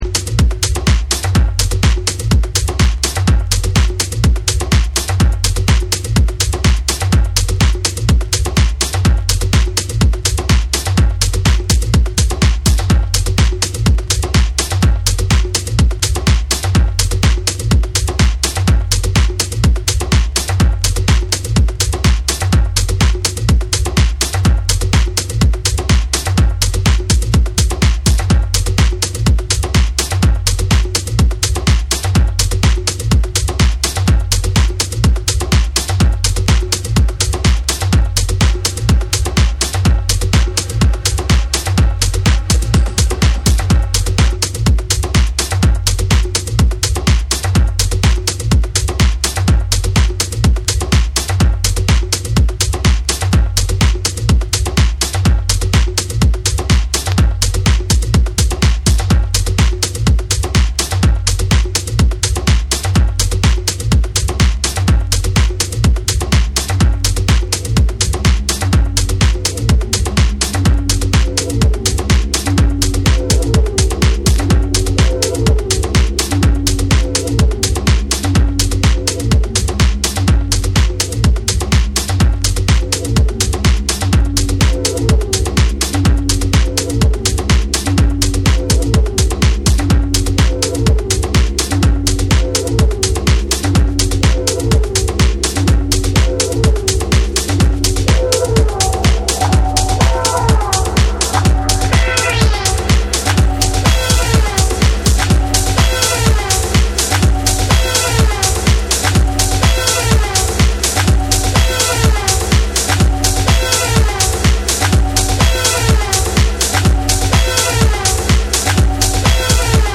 ディープかつ機能的なグルーヴでフロアにじわりと効く2曲を収録。
TECHNO & HOUSE / ORGANIC GROOVE